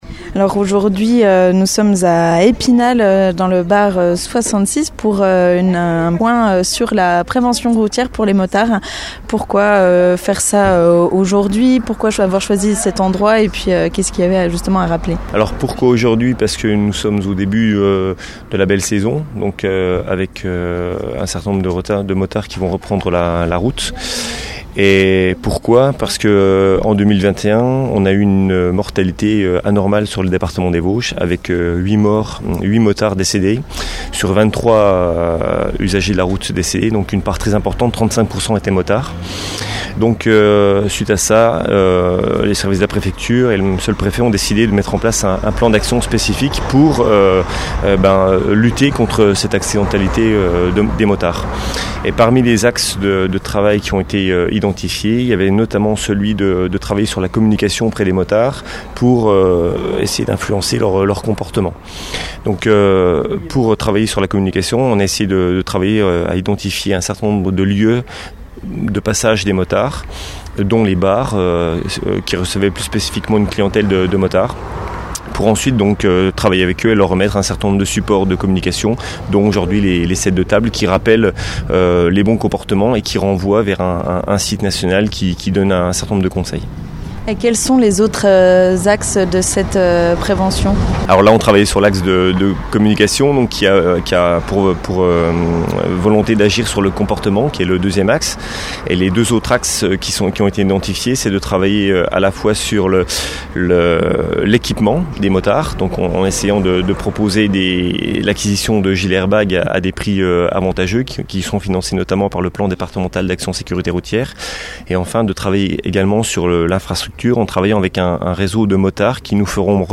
Après avoir rappelé que l'année derninère, 8 motards sont mort sur les routes du département, il a répondu à nos questions.